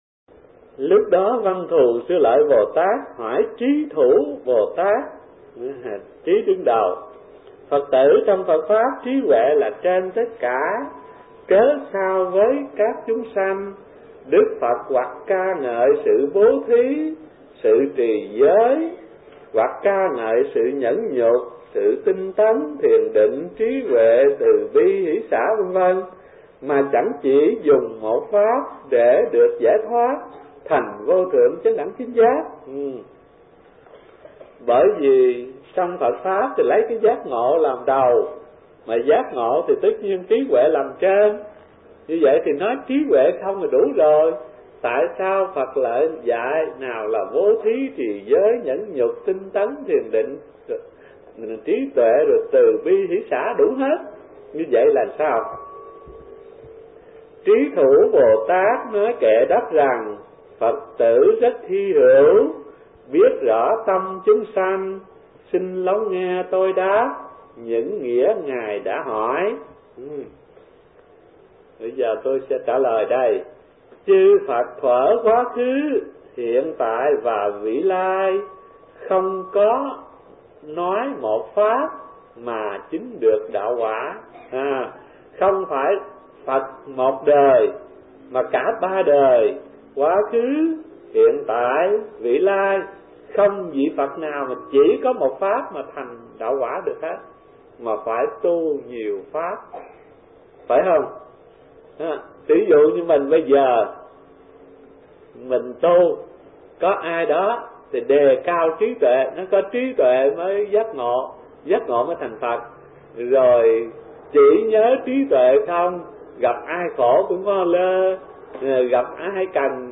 Kinh Giảng Kinh Hoa Nghiêm (Thiền Viện Linh Chiếu) - Thích Thanh Từ